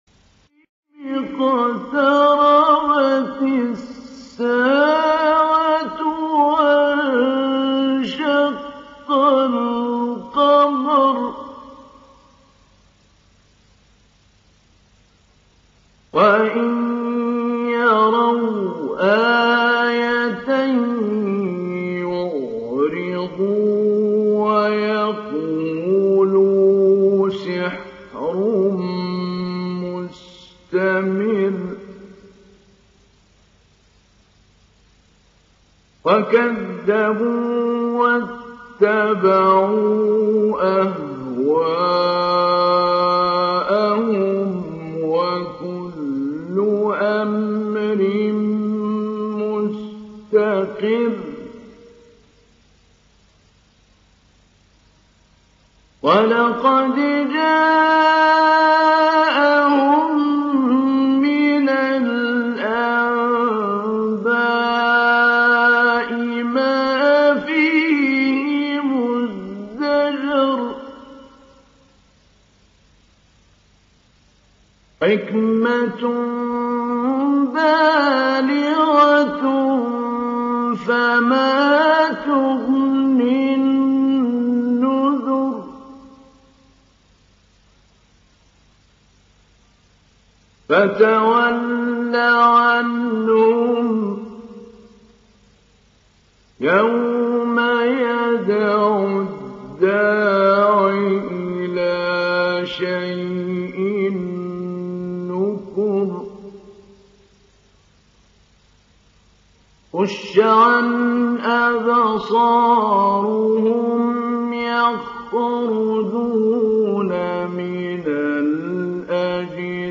Download Surah Al Qamar Mahmoud Ali Albanna Mujawwad